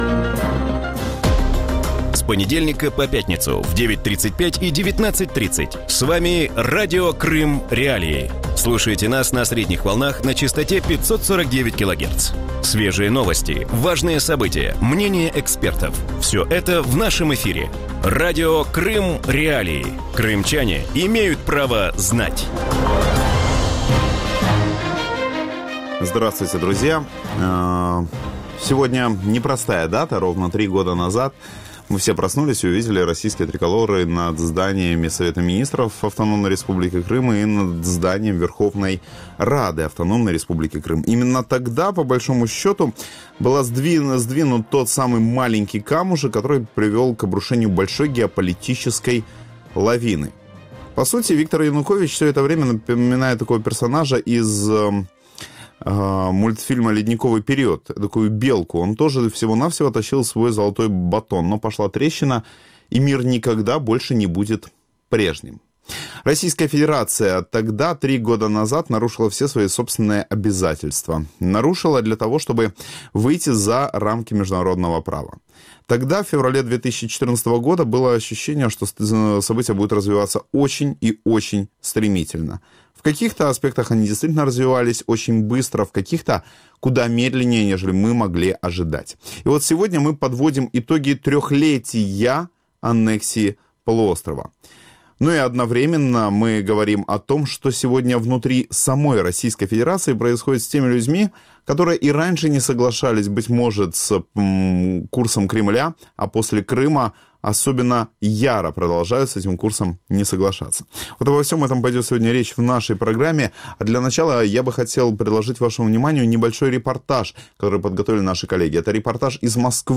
В вечернем эфире Радио Крым.Реалии говорят о том, что происходит с Россией спустя три года после аннексии. Как прошли марши памяти Бориса Немцова в Москве и других городах, на что способна российская оппозиция и как россияне протестуют против репрессий в Крыму?